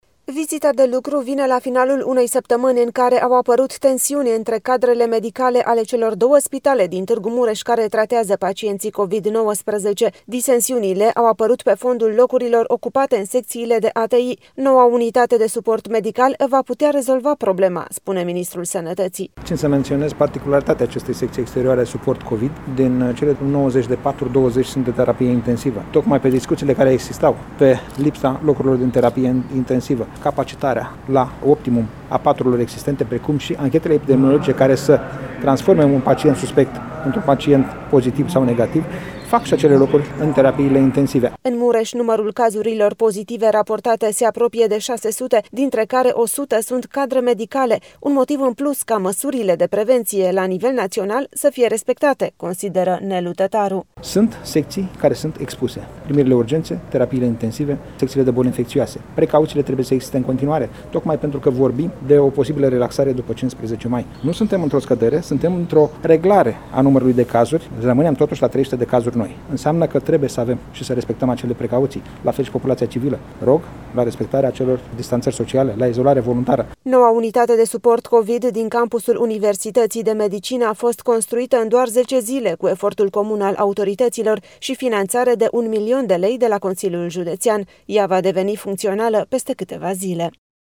Noua unitate de suport medical va putea rezolva problema, spune Ministrul Sănătății: